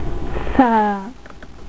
"Saa" se prononce comme "sa" en français, mais faites attention à le faire suffisament long.
Il se commence par un ton assez haut, et dois terminer plus bas.